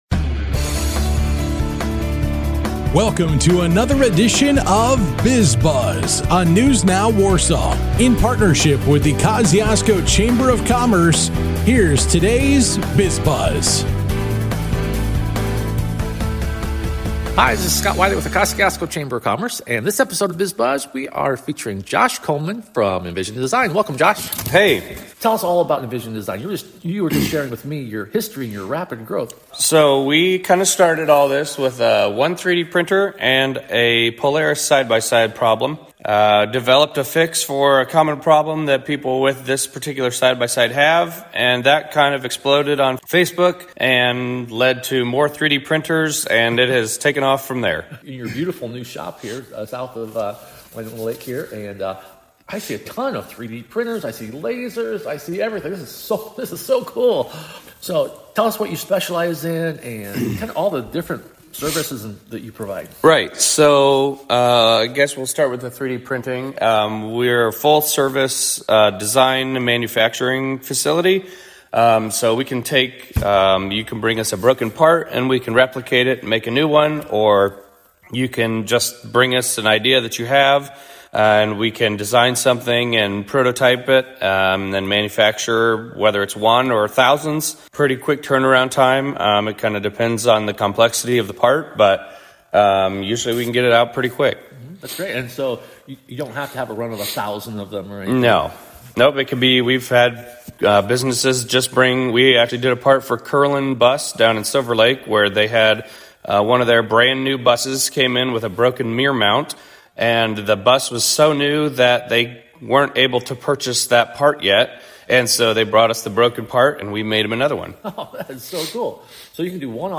A chat with Envision Design and Refuge Counseling and Consulting